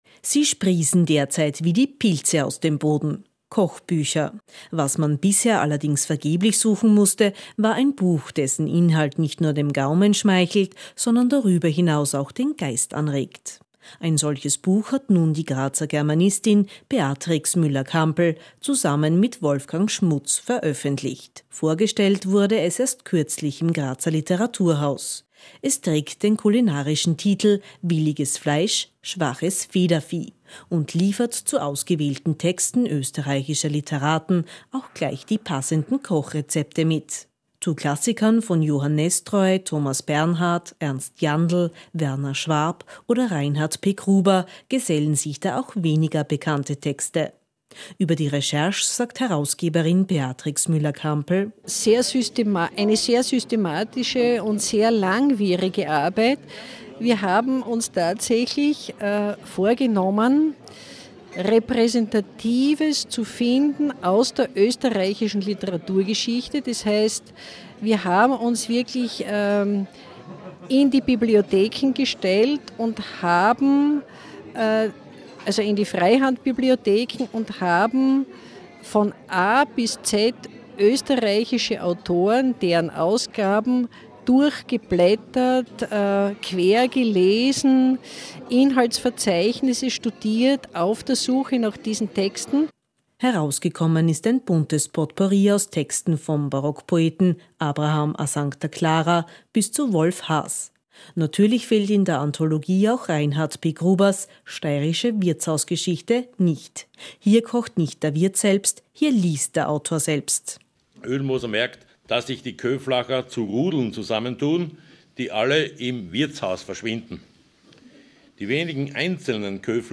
Von der Buchpräsentation im Grazer Literaturhaus berichtet der